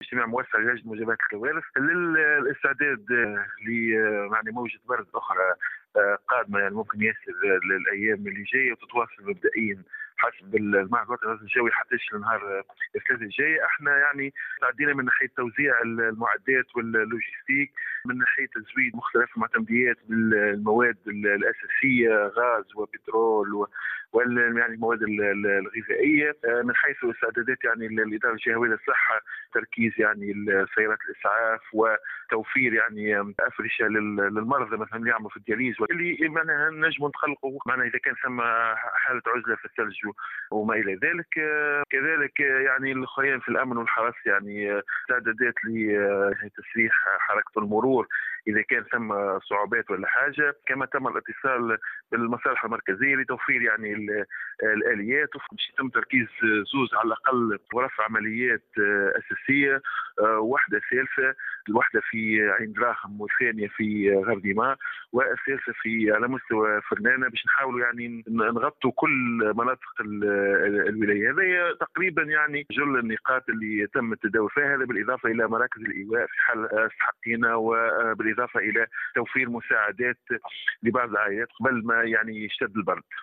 و أكد والي جندوبة، نجيب الخبوشي اليوم في تصريح ل"جوهرة أف أم" أنه تم اتخاذ كافة الإجراءات الضرورية للتوقي من تساقط الثلوج والأمطار بالمنطقة،حيث تم بالتنسيق مع وزارة الصحة تركيز فرق لتقديم النجدة والإسعاف وتزويد الجهات المعنية بالمواد الغذائية والغاز والبترول والحطب،فضلا عن اتخاذ التدابير اللازمة من قبل وحدات الشرطة وحرس المرور للتدخّل السريع عند الاقتضاء.